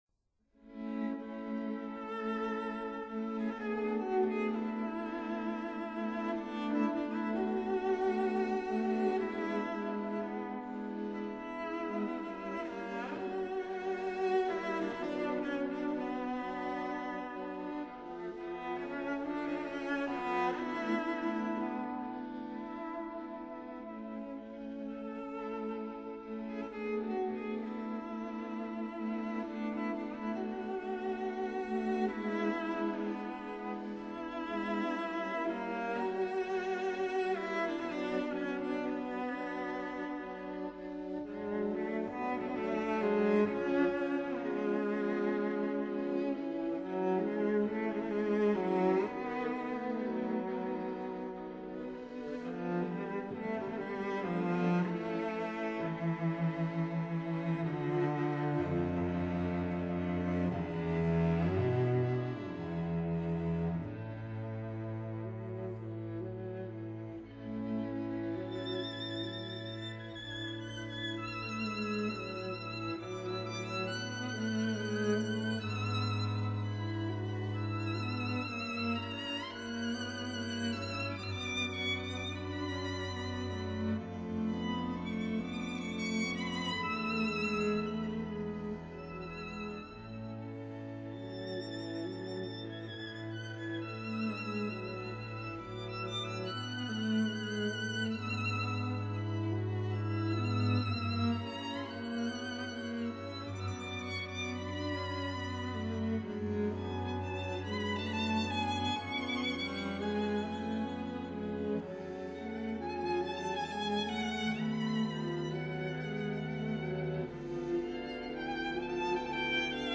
鋼琴五重奏
D大調第二號弦樂四重奏
•(01) Piano Quintet in C minor
•(07) String Quartet No. 2 in D major